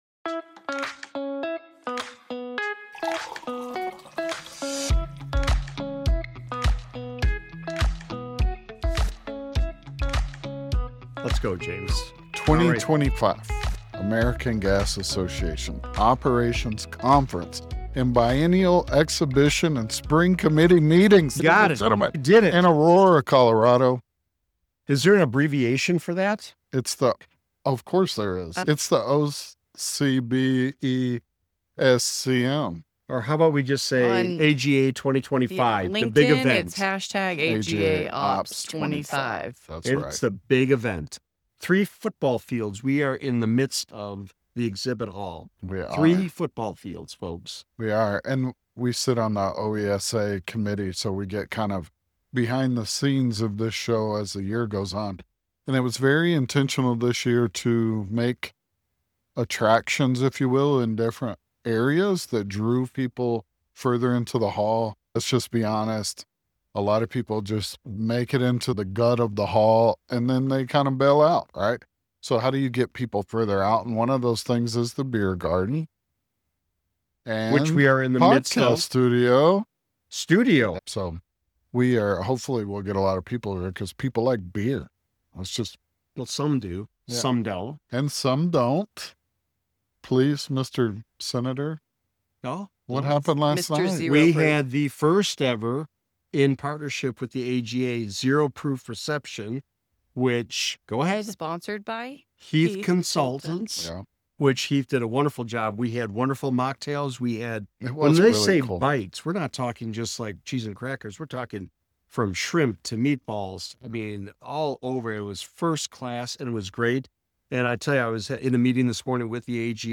Live @ American Gas Association Operations Conference